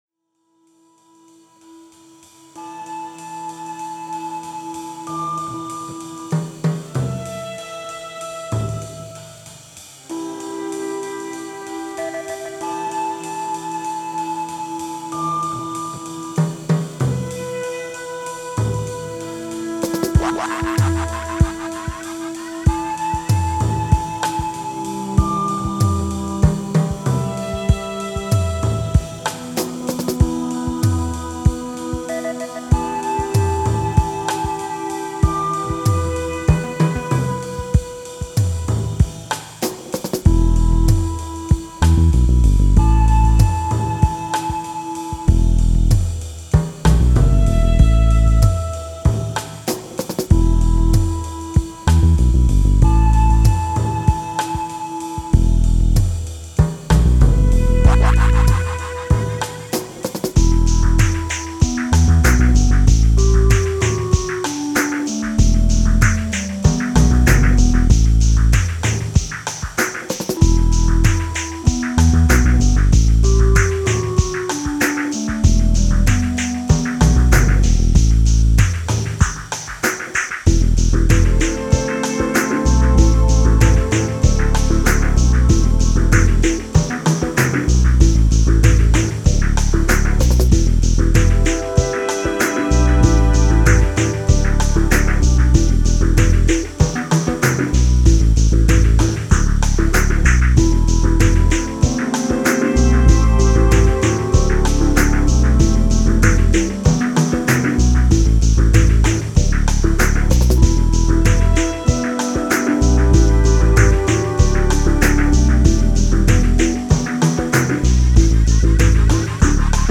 Genre: Psychill, Downtempo, IDM.